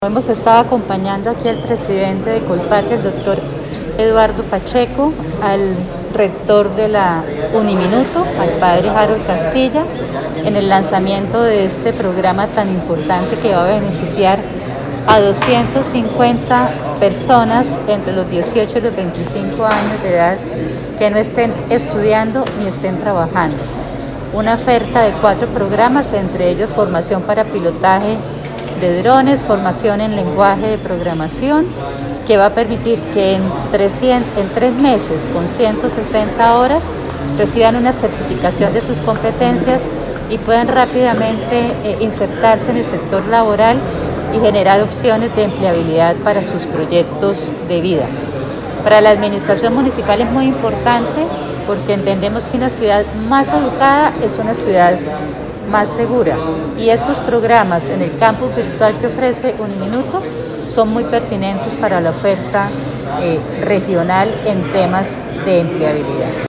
Audio: Ana Leonor Rueda, secretaria de Educación de Bucaramanga